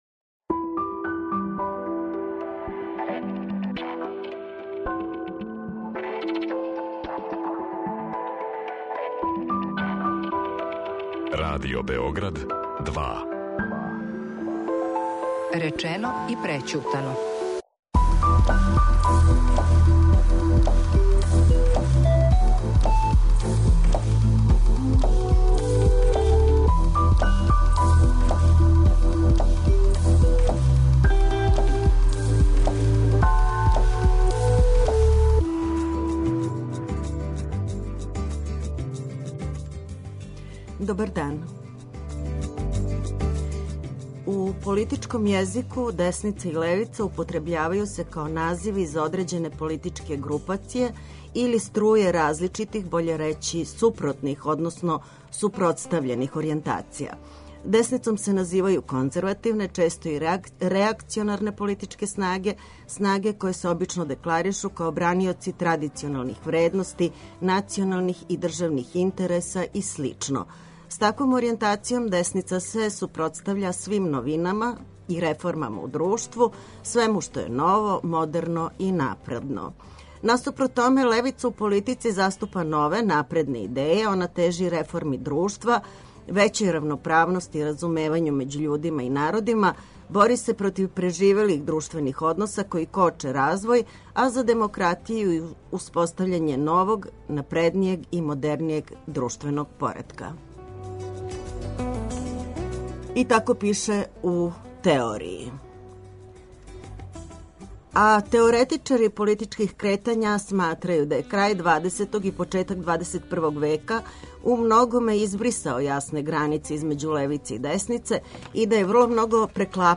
говоре представнице новоформираних странака с лева и с десна